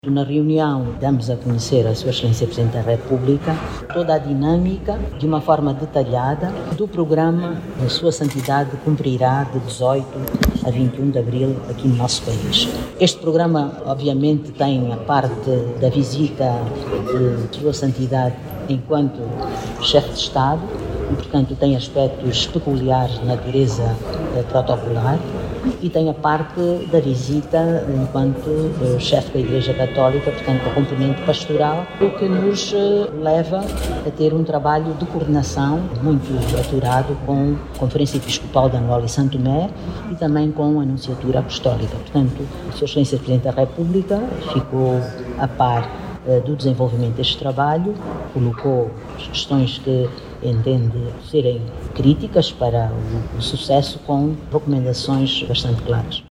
Na ocasião, a Ministra de Estado para a Área Social, Maria do Rosário Bragança, garantiu empenho do nosso país para que a peregrinação do Papa decorra com êxito.
MARIA-DE-ROSARIO-BRAGANCA-1-06HRS.mp3